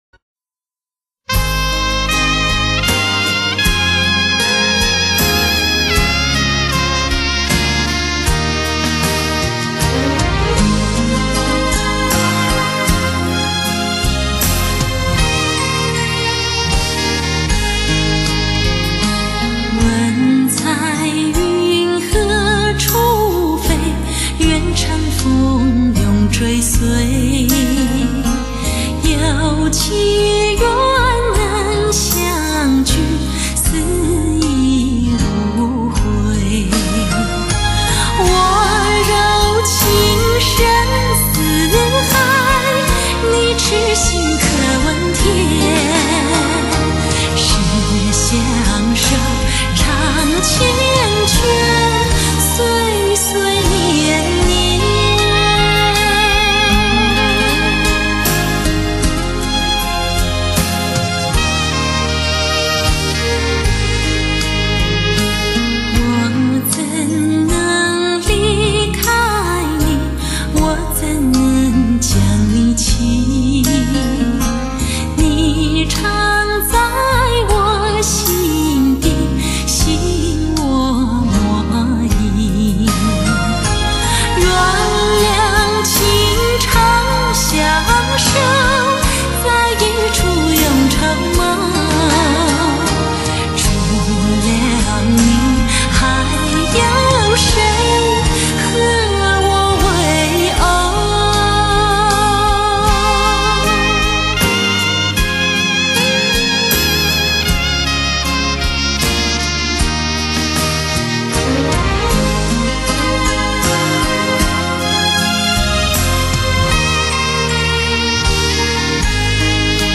發燒極品，百聽不厭;全頻段六聲道製作如同置身音樂聽之中。傳奇真空管處理，頂級音效環繞體！